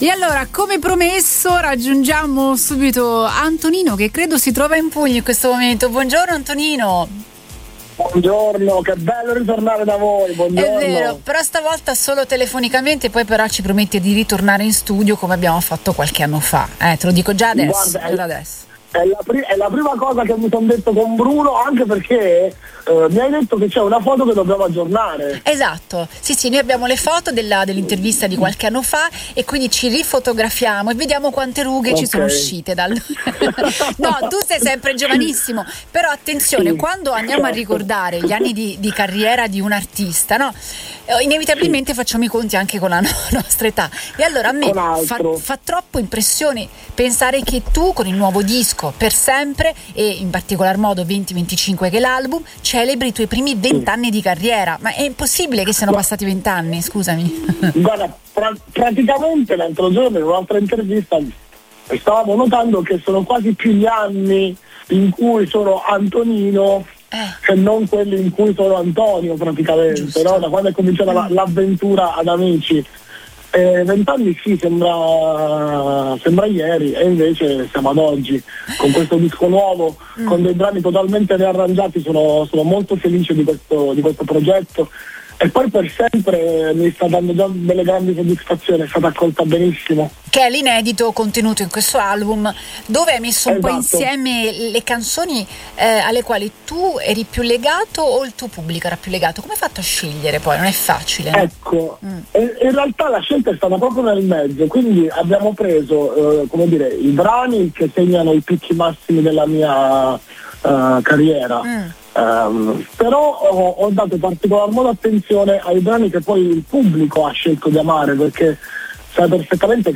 Il cantautore Antonino è tornato in collegamento con Radio Punto Nuovo per festeggiare un traguardo eccezionale: i suoi primi 20 anni di carriera. L’artista, vincitore di Amici nel 2005, ha presentato il suo nuovo progetto discografico, l’album “20-25”, che include l’inedito “Per sempre” .